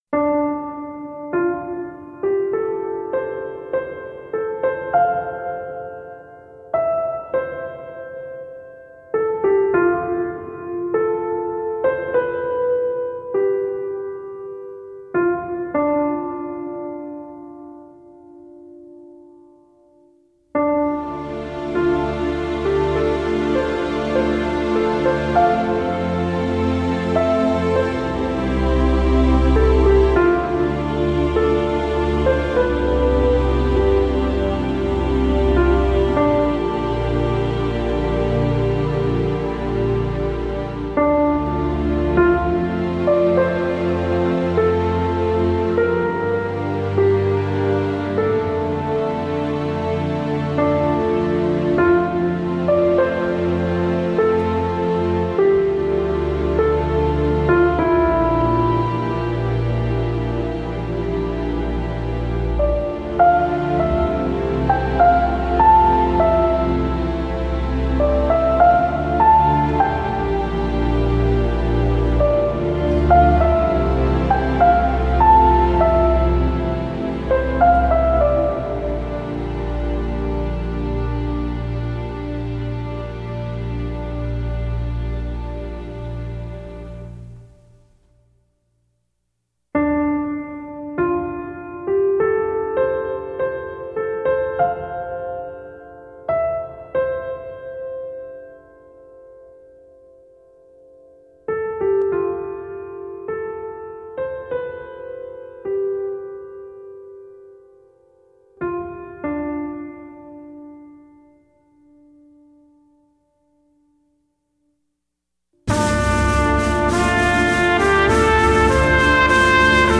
Thème musical du film